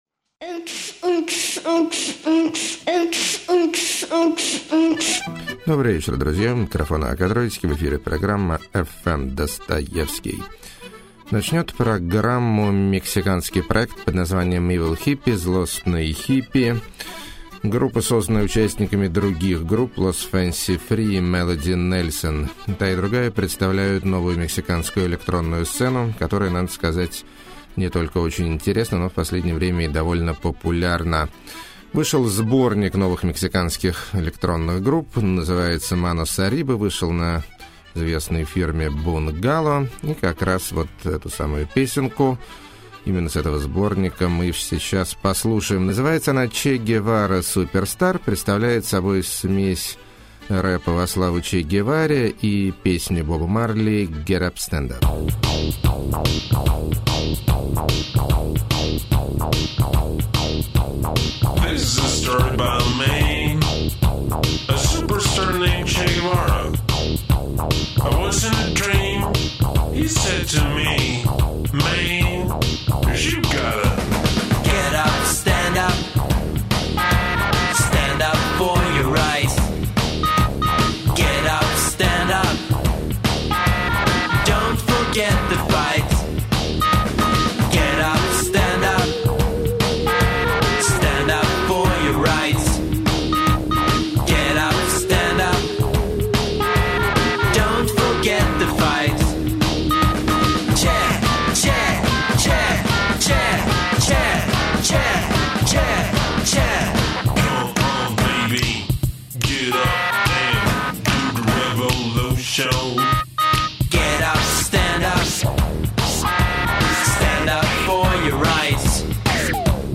Chanson Moderne Weirdo
Sensual Garage
Depresso-polka
Acid Guitar Trip Temple
Melancholia With Funny Sounds
Passionate Funk, Great Voice